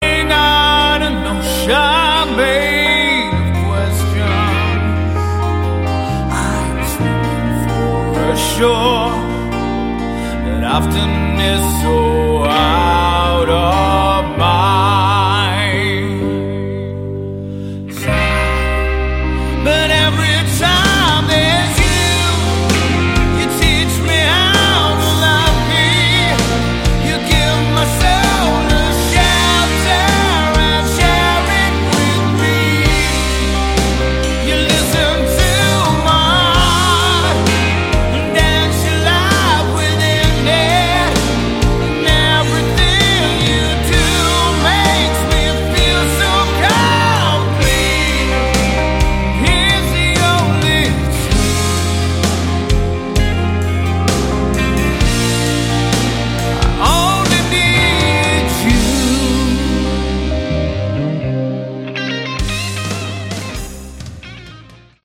Category: AOR/Melodic Rock
It's simply good, straight ahead rock.